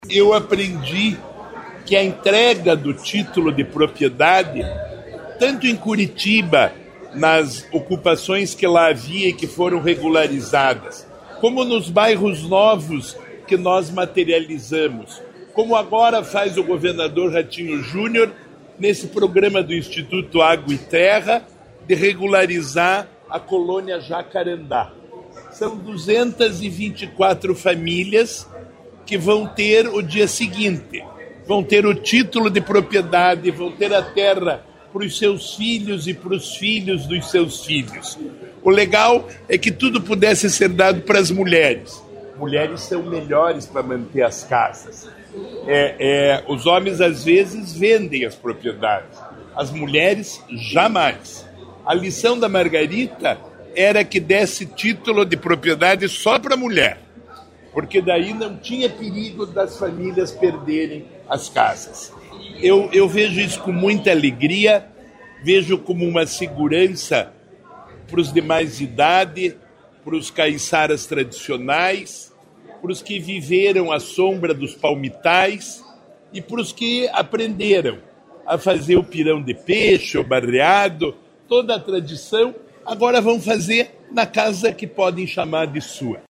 Sonora do secretário Estadual do Desenvolvimento Sustentável, Rafael Greca, sobre a entrega de títulos de propriedade de imóvel para 224 famílias do Litoral